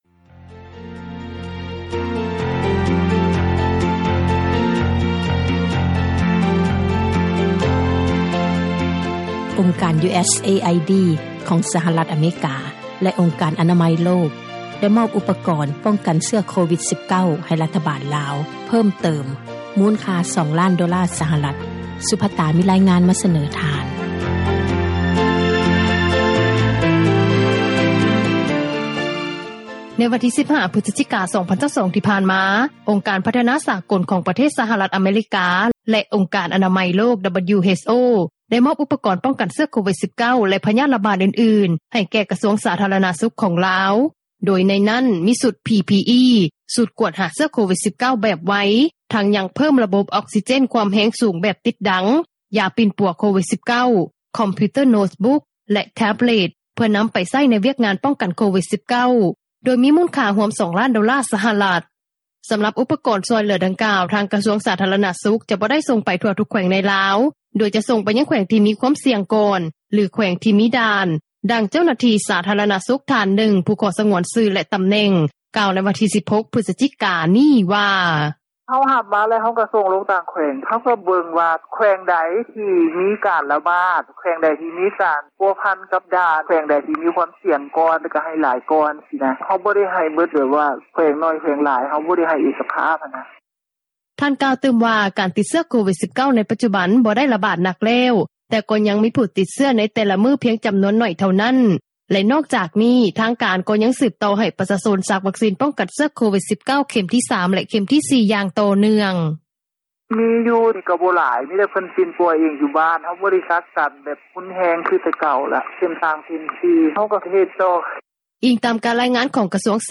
ດັ່ງເຈົ້າໜ້າທີ່ ສາທາຣະນະສຸຂທ່ານນຶ່ງ ຜູ້ຂໍສງວນຊື່ ແລະຕໍາແໜ່ງ ກ່າວໃນວັນທີ 16 ພຶສຈິກາ ນີ້ວ່າ: